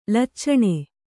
♪ laccaṇe